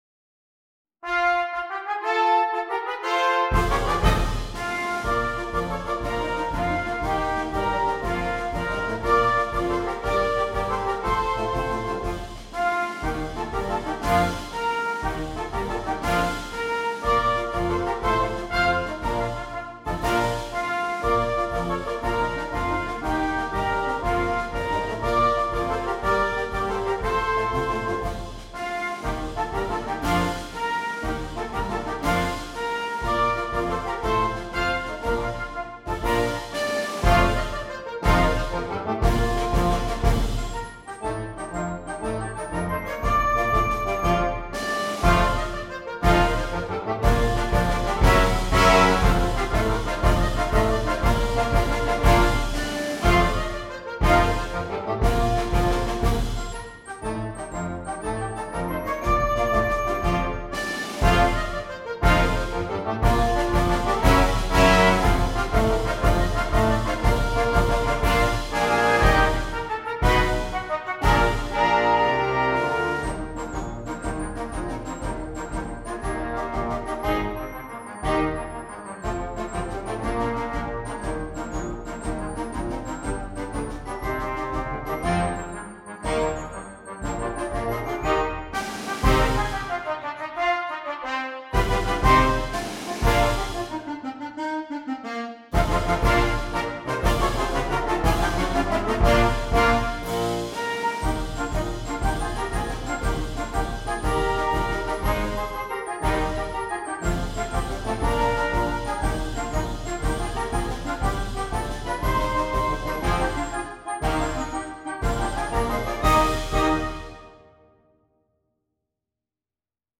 Voicing: Flex March